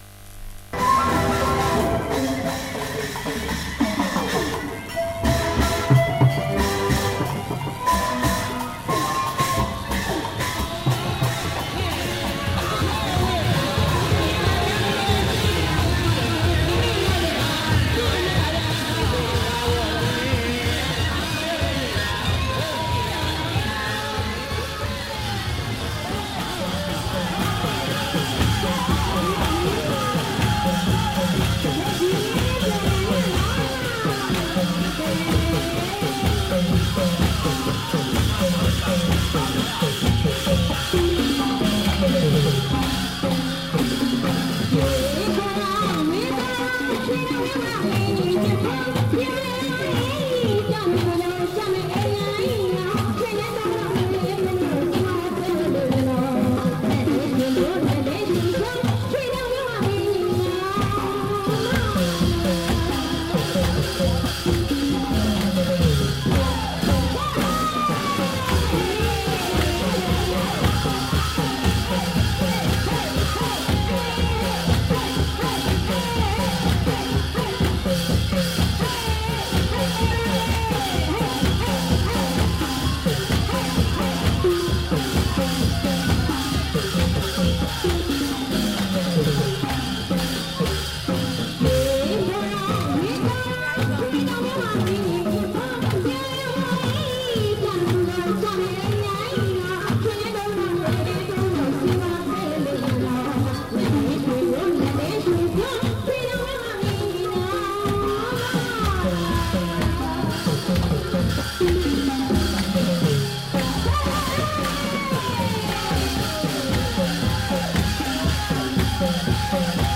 詩的かつサイケデリックなアンビエンスを醸し出した音を楽しめる、東南アジアのフィールド録音作品！
※レコードの試聴はノイズが入ります。商品自体のノイズではありません。